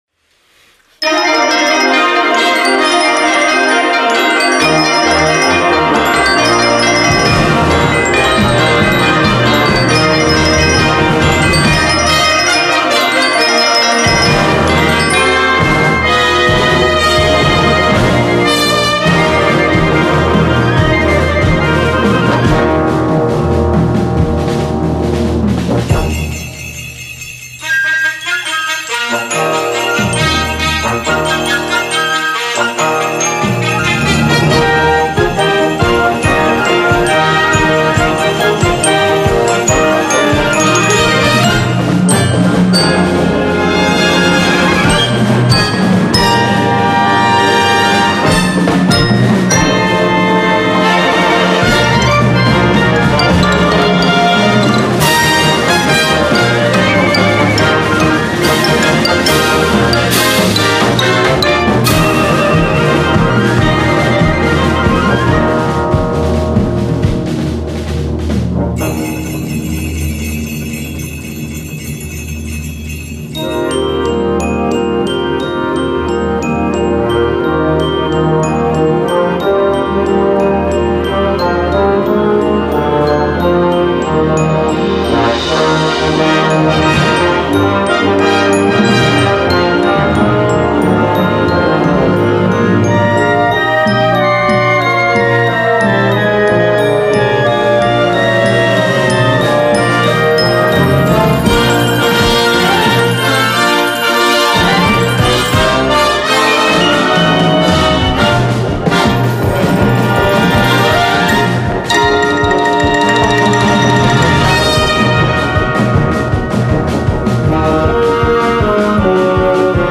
Gattung: Concert Band
Besetzung: Blasorchester